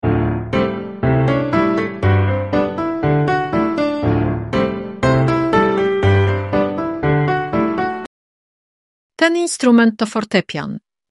1⃣ Instrumenty muzyczne
• Odtwarzanie dźwięku instrumentu.
fortepian.mp3